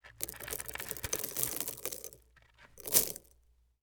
Sand_Pebbles_23.wav